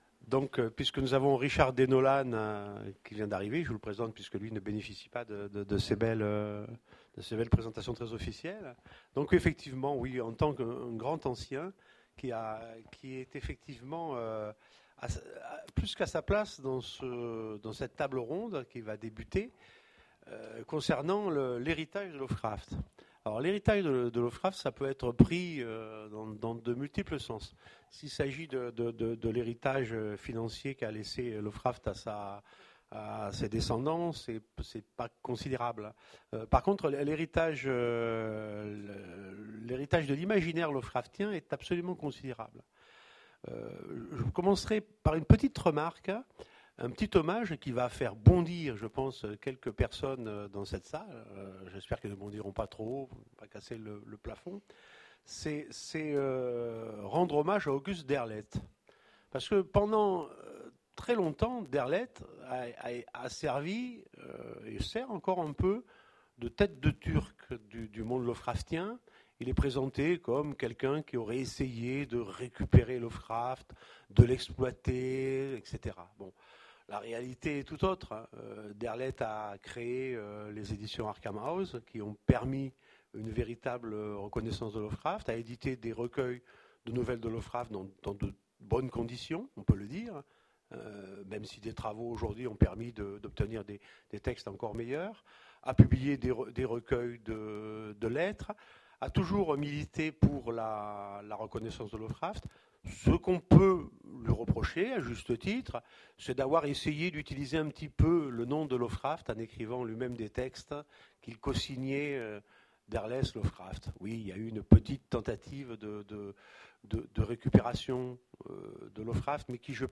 Voici l'enregistrement de la conférence L’héritage de Lovecraft lors des 7ème rencontres de l'Imaginaire de Sèvres.